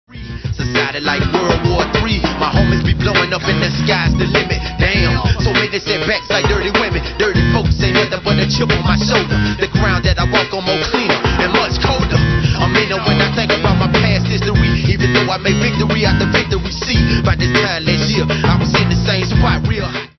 rap
stereo